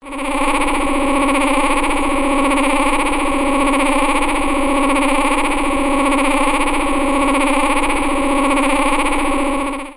Alesis Micron的提示音和嘟嘟声 " Micbl11
描述：调制的蜜蜂声音的嗡嗡声。用Alesis Micron.
Tag: ALESIS 嗡嗡声 微米 调制器 合成